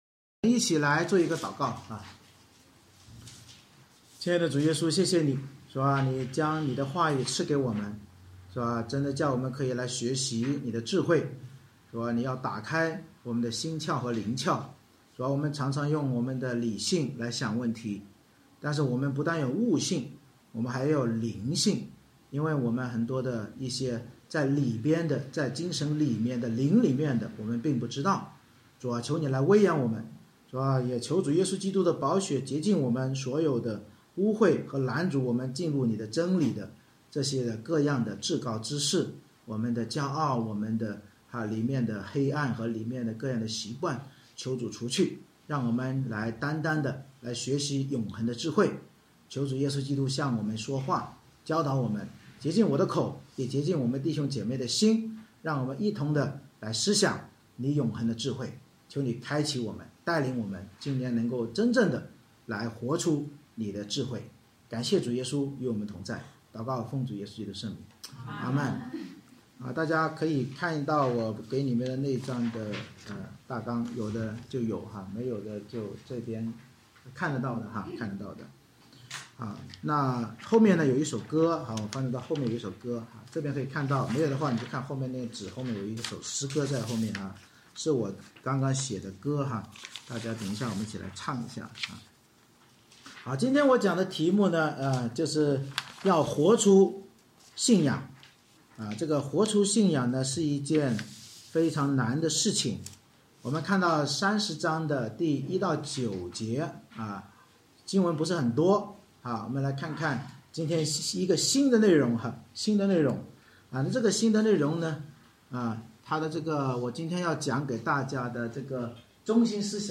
箴言30：1-9 Service Type: 主日崇拜 通过智慧人亚古珥的真言，教导我们要经历真实信仰首先要谦卑认罪才能认识创造主与神儿子基督，并以圣经为中心活出祷告的生命。